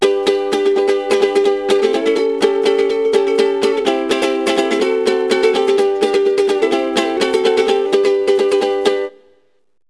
ukulele.wav